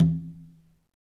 Conga-HitN_v1_rr1_Sum.wav